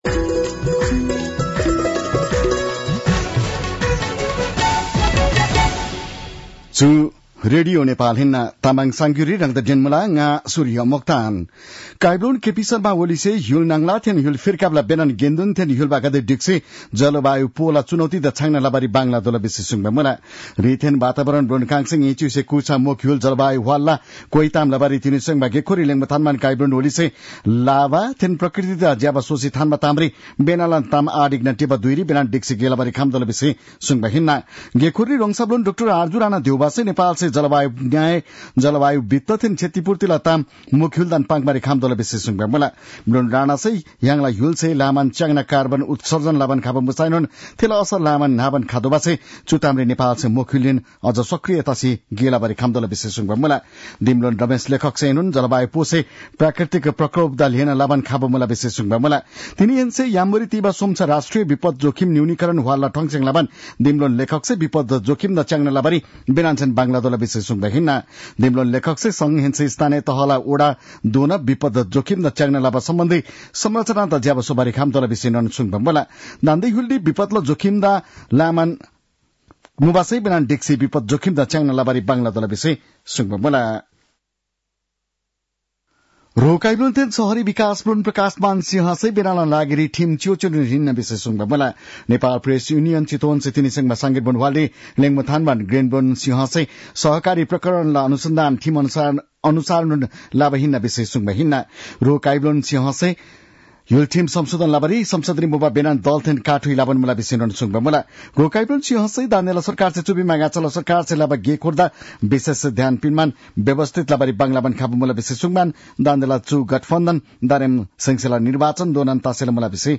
तामाङ भाषाको समाचार : १२ पुष , २०८१